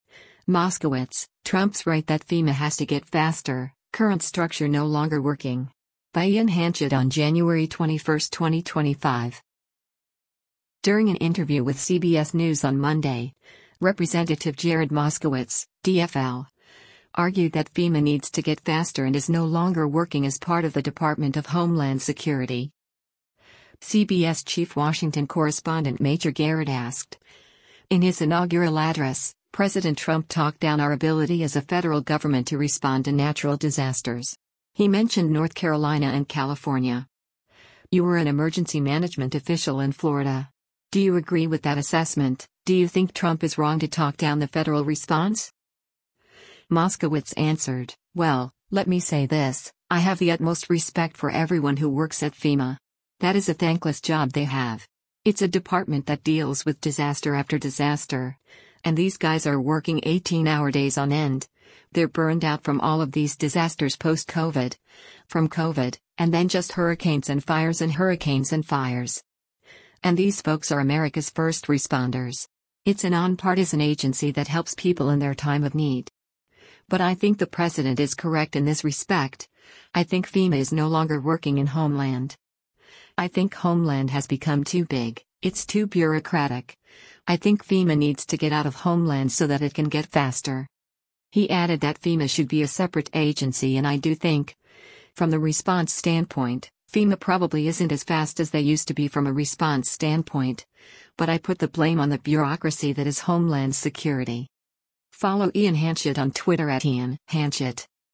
During an interview with CBS News on Monday, Rep. Jared Moskowitz (D-FL) argued that FEMA needs to get faster and is “no longer working” as part of the Department of Homeland Security.